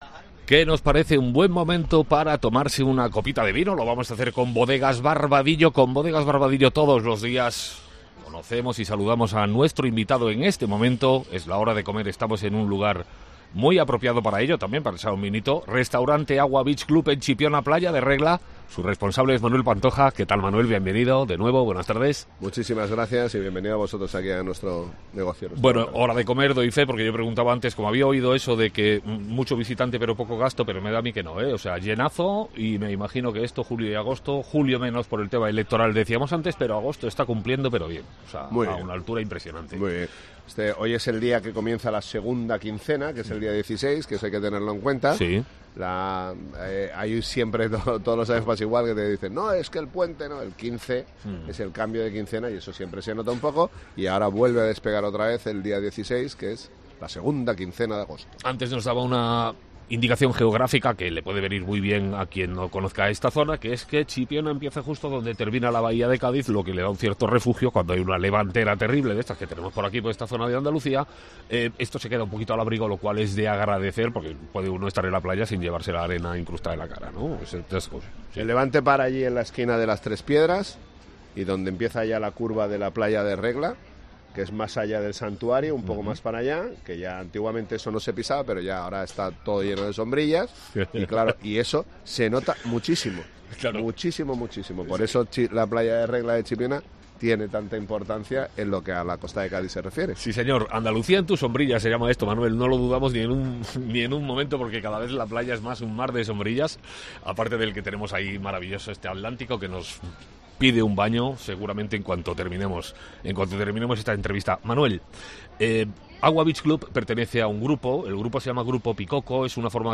La sombrilla de COPE ANDALUCÍA ha visitado la Playa de Regla de Chipiona.